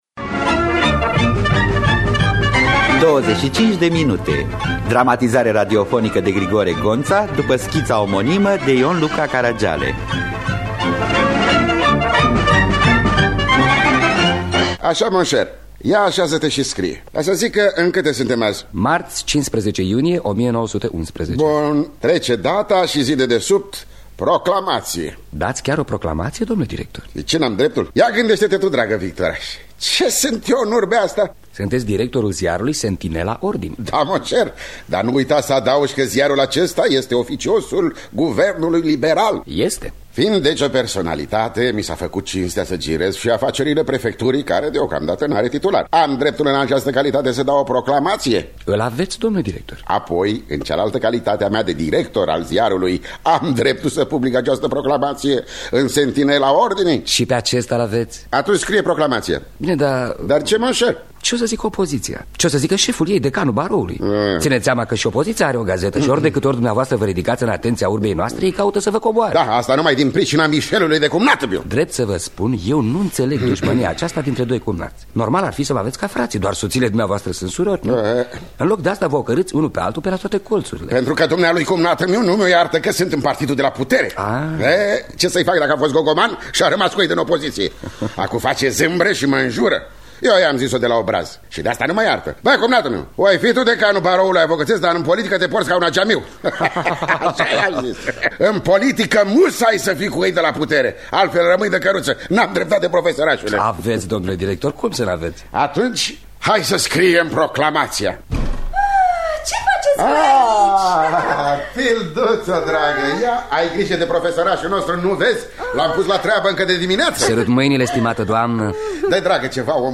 Înregistrare din anul 1983.